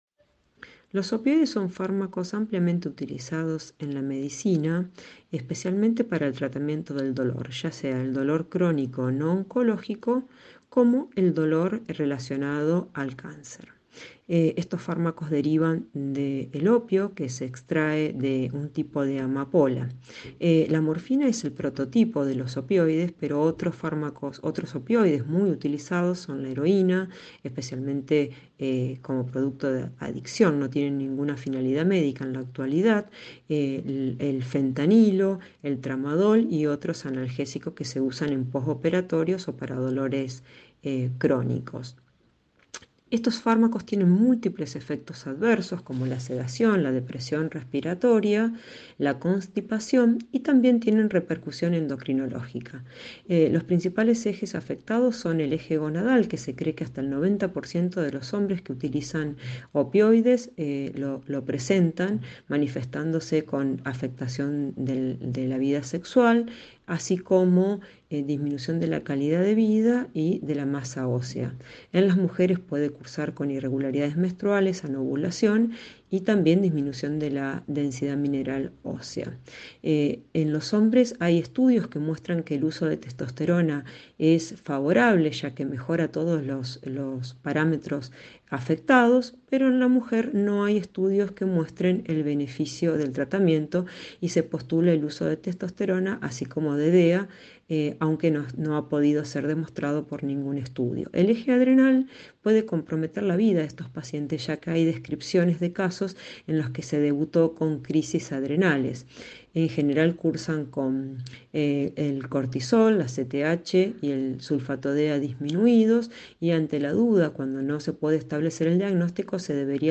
Ateneos médicos
Incluye preguntas y respuestas